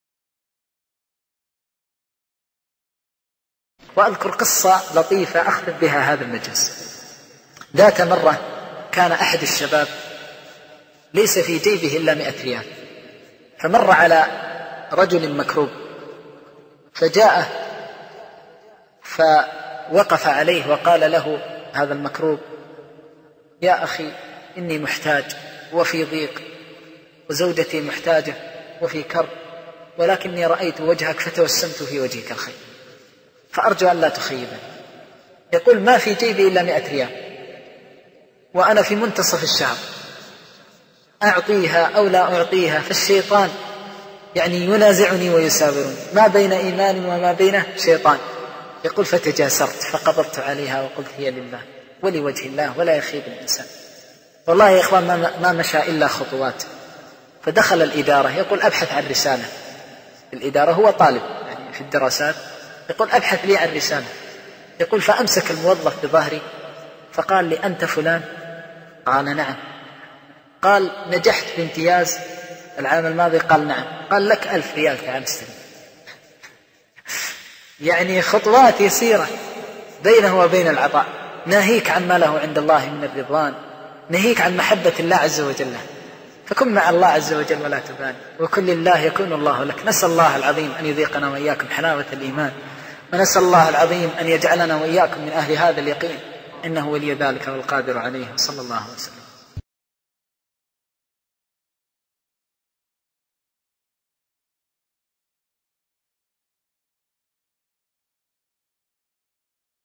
من محاضرة الاعتصام بالله